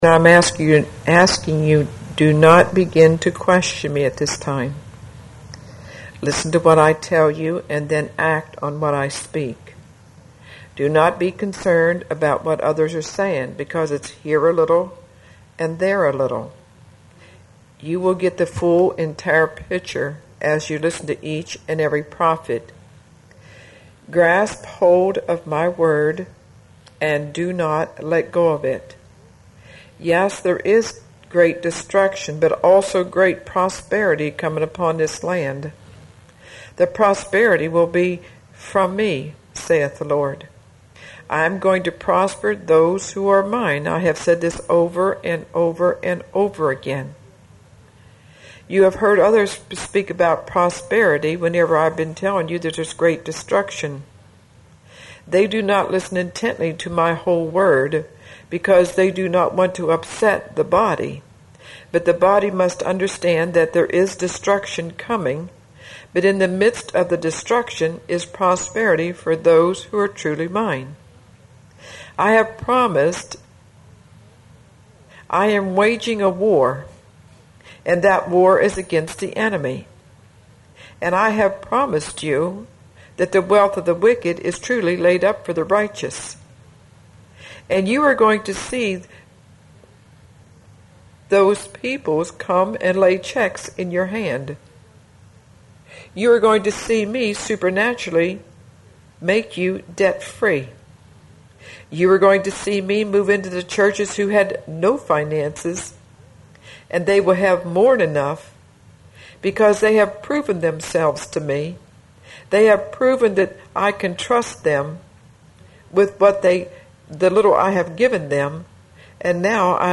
January 2007 Prophetic Word
The Father spoke this prophetic word through me at an evening service at The Lighthouse Inc Church on January 2, 2007 and I know this is the time this particular word is coming to pass.